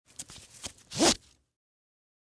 11. Звук связывания веревки для монтажа